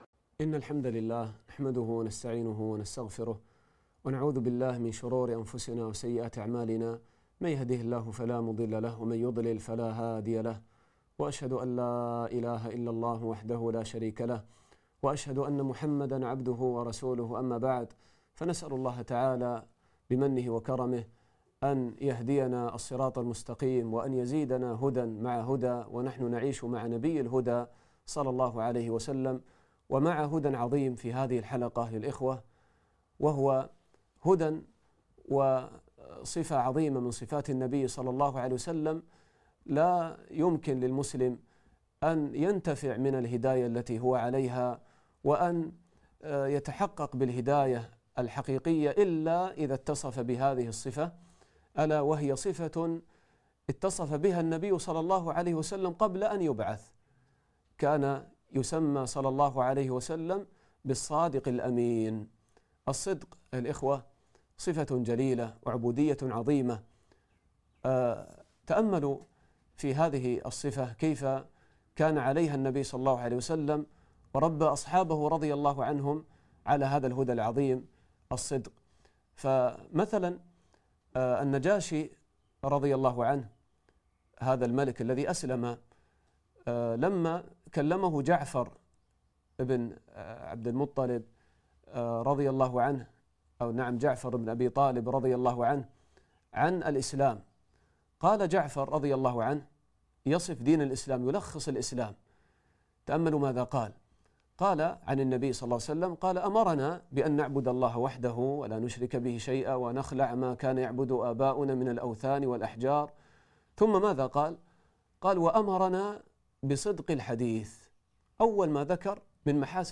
الدرس الخامس